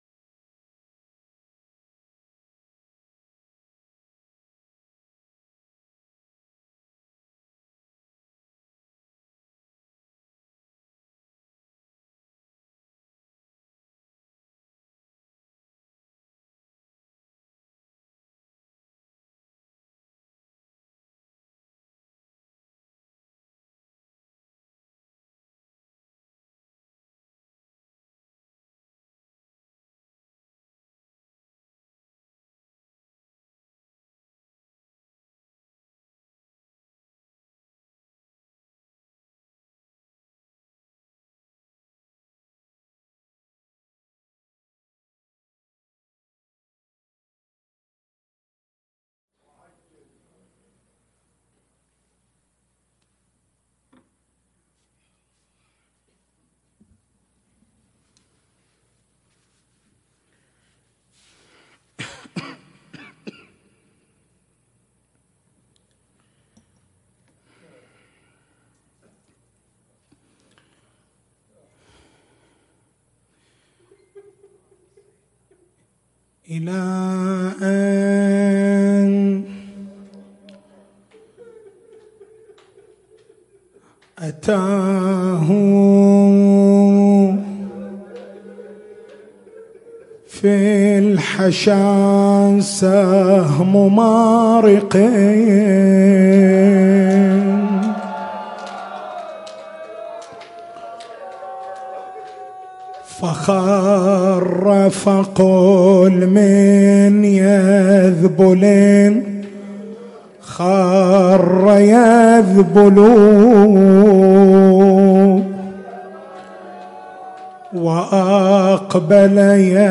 حسينية بن جمعة بالكويكب حسينية عمارة بالربيعية حسينية الزين بالقديح
مكتبة المحاضرات